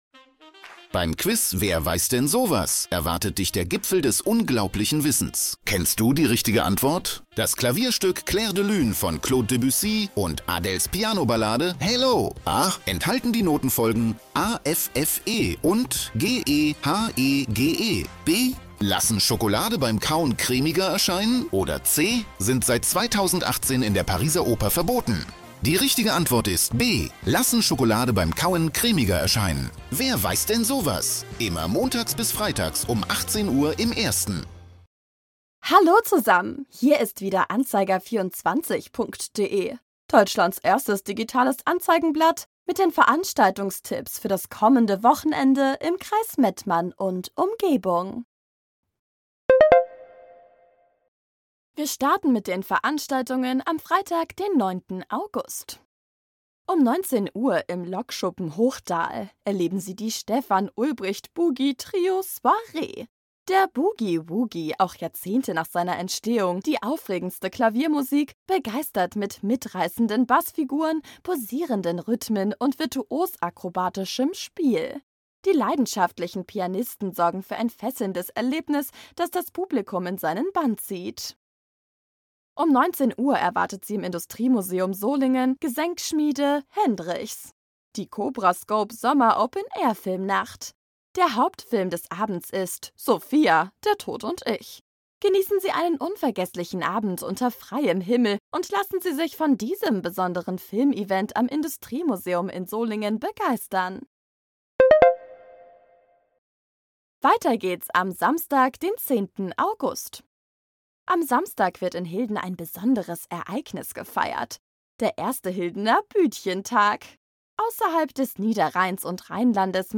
„Musik“